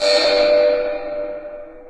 Стандартный сигнал "тревога".